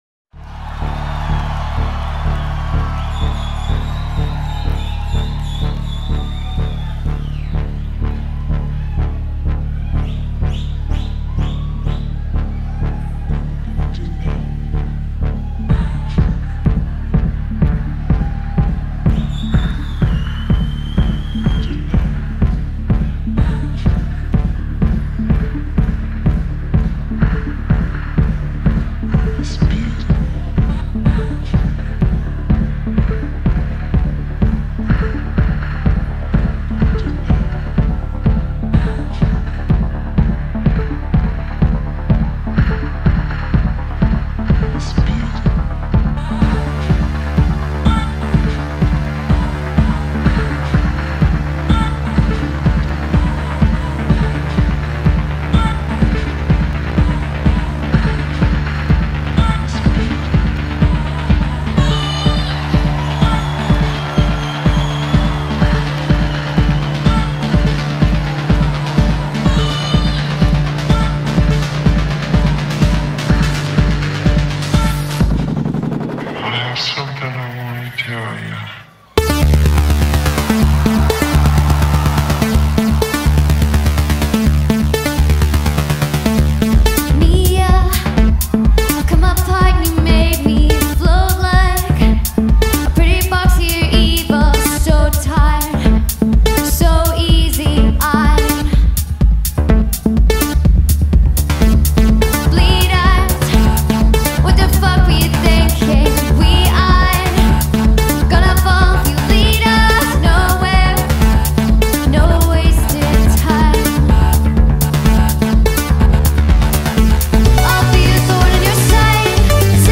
recorded at the Pitchfork Festival in Paris